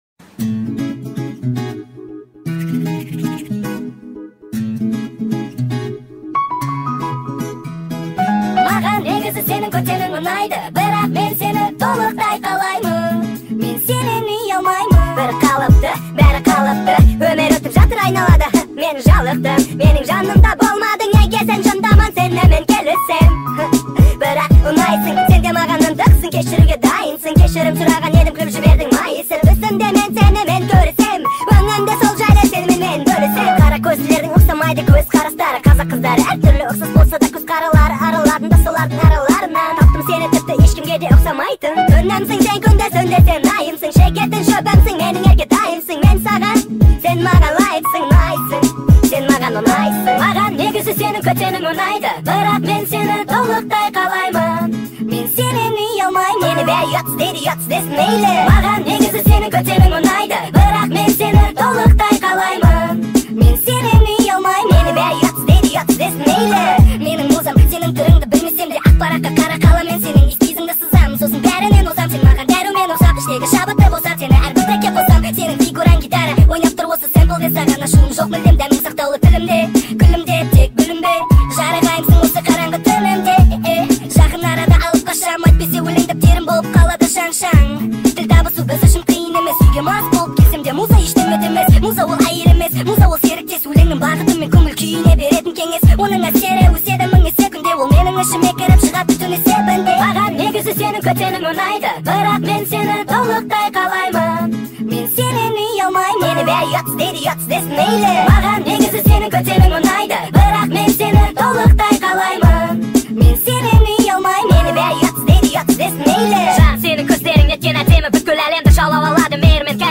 sped up
TikTok remix